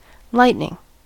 lightning: Wikimedia Commons US English Pronunciations
En-us-lightning.WAV